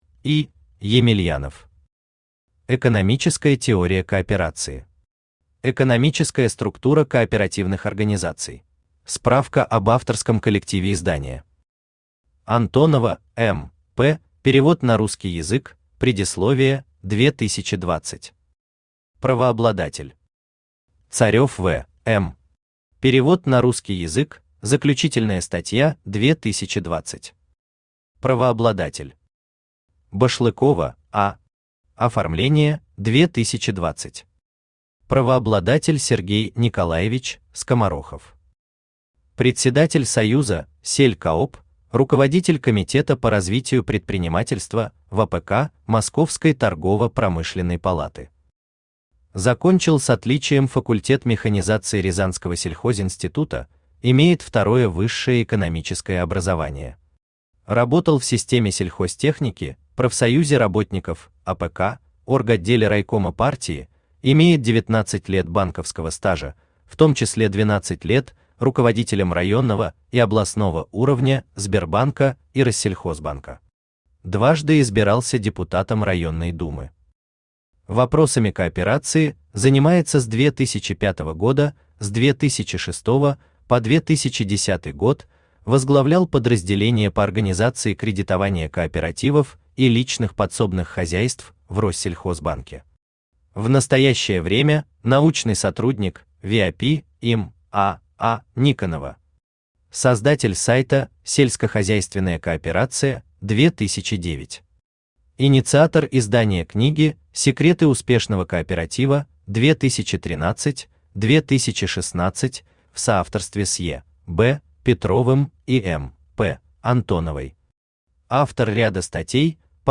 Аудиокнига Экономическая теория кооперации. Экономическая структура кооперативных организаций | Библиотека аудиокниг
Экономическая структура кооперативных организаций Автор И. В. Емельянов Читает аудиокнигу Авточтец ЛитРес.